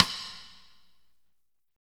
Index of /90_sSampleCDs/Northstar - Drumscapes Roland/KIT_R&B Kits/KIT_R&B Dry Kitx
SNR P C S0XL.wav